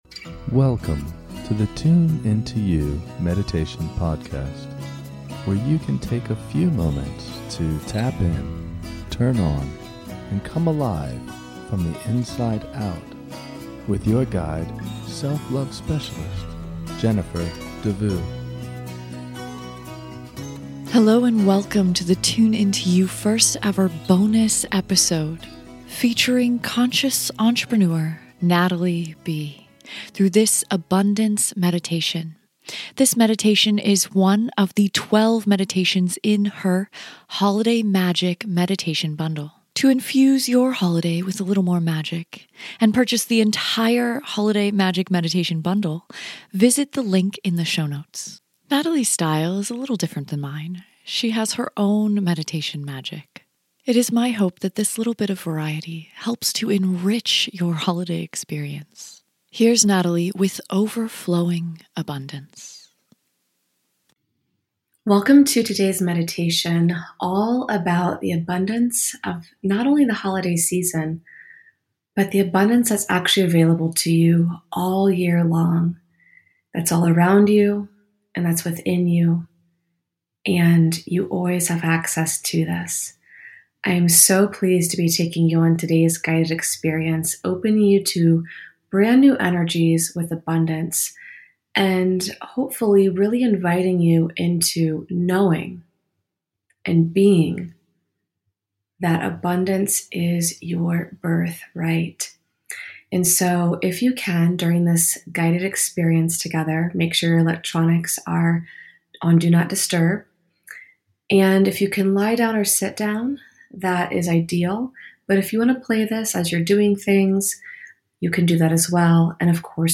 guided meditations